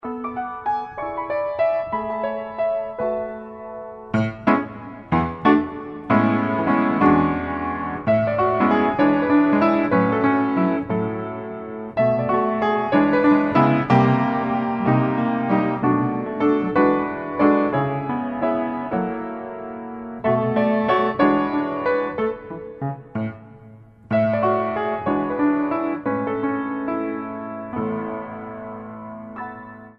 В стиле Джаз